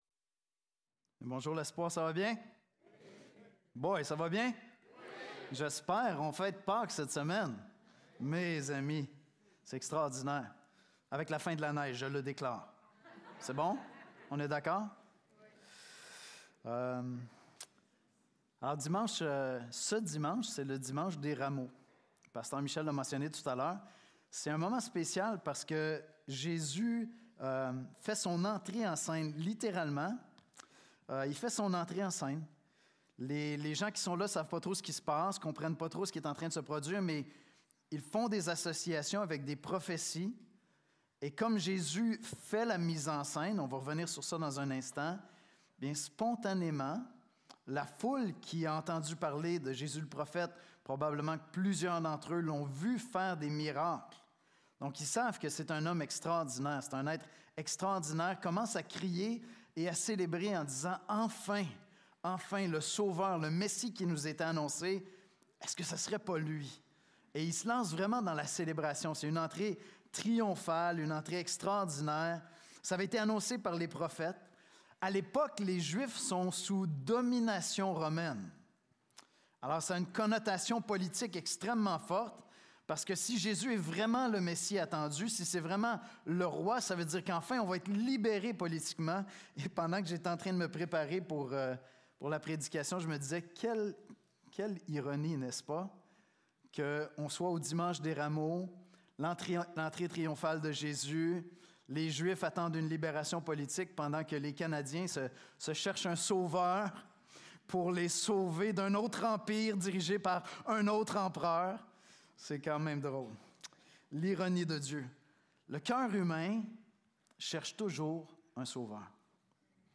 Nous vous présentons quelques unes des exposés bibliques apportés à l'Église de l'Espoir en baladodiffusion.